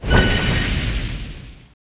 Index of /App/sound/monster/ice_snow_monster
attack_1.wav